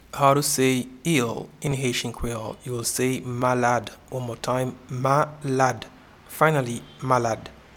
Pronunciation and Transcript:
ill-in-Haitian-Creole-Malad.mp3